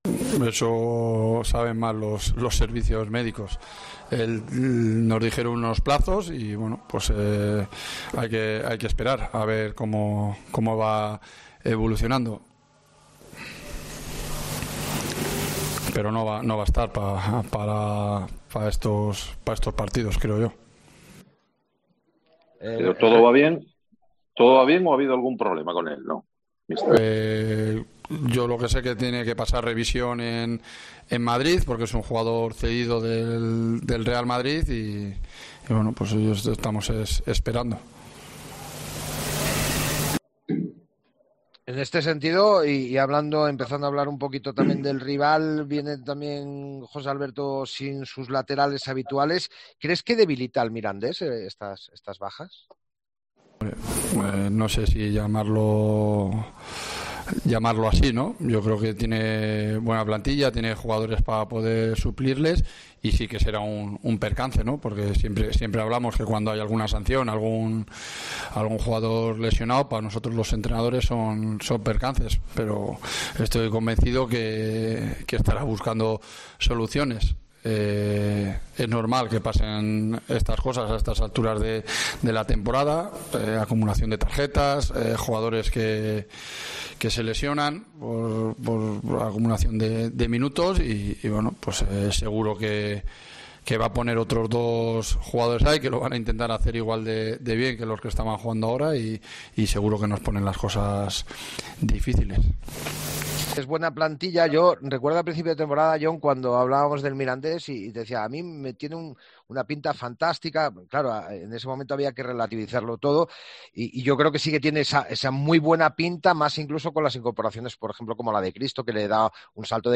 AUDIO: Escucha aquí las palabras del entrenador de la Ponferradina